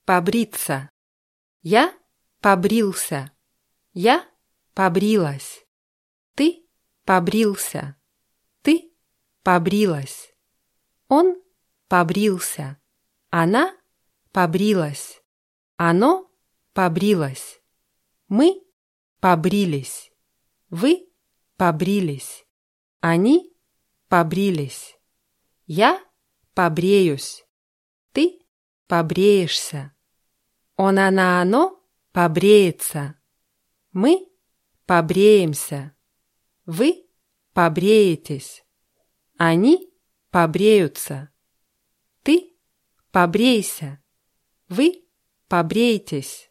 побриться [pabrʲítsa]